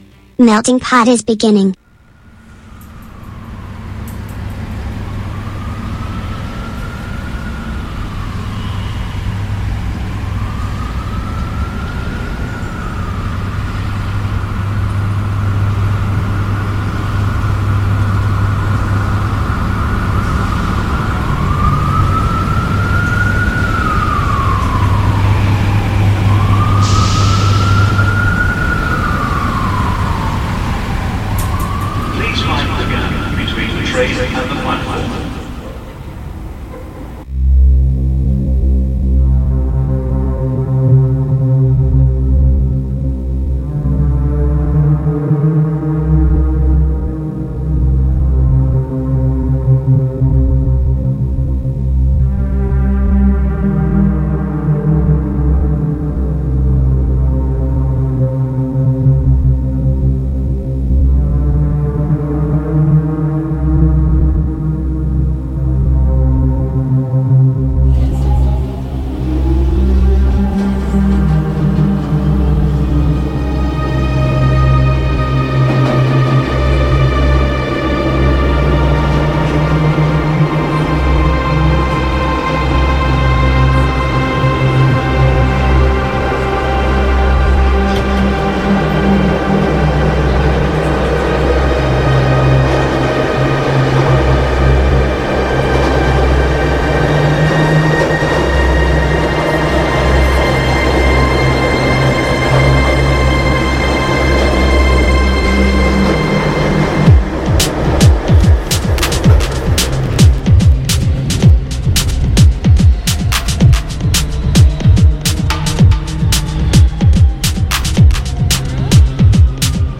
La serata si è aperta con la consueta clip cinematografica — stavolta tratta da Signore e Signori, Buonanotte — per poi scivolare in un viaggio musicale che ha toccato il pop, l’elettronica, l’indie e le nuove tendenze della scena italiana e internazionale.